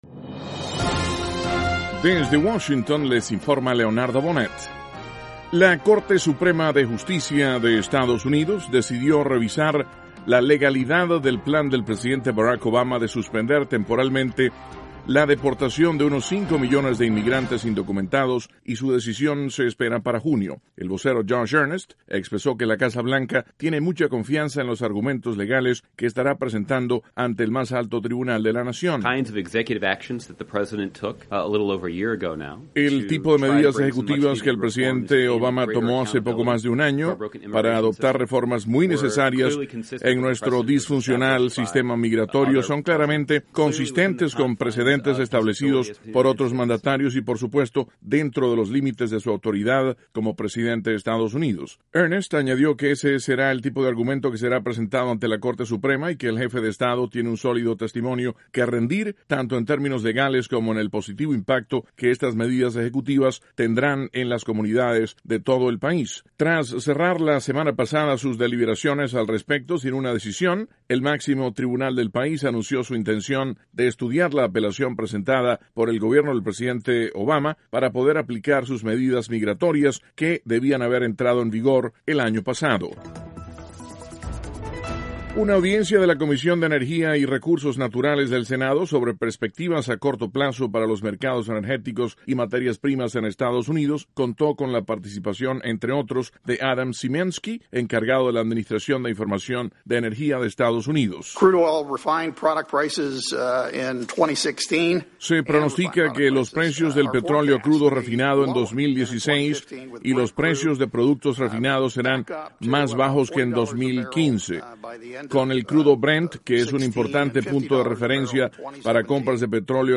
VOA: Noticias de la Voz de América - Martes, 19 de enero, 2016